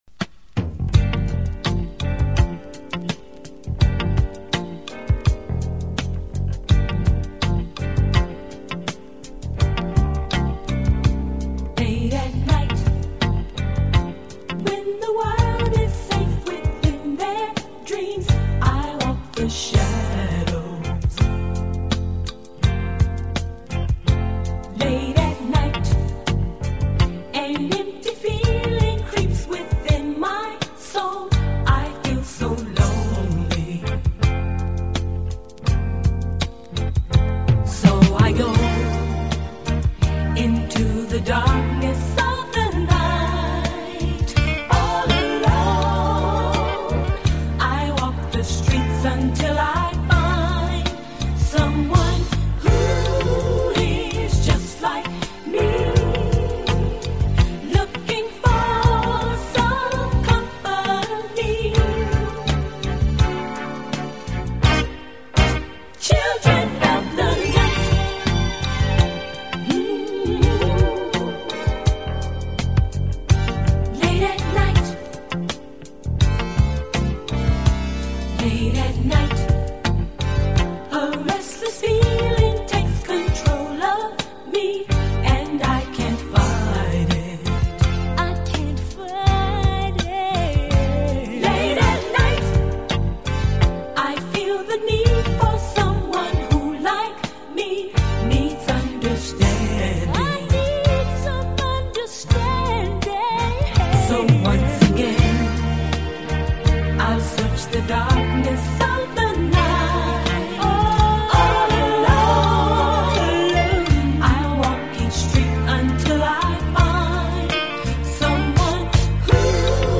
вокальное трио состоящее из сестёр Бреды
Работали в стиле R&B, Funk, Disco.